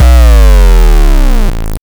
1 weird 808.wav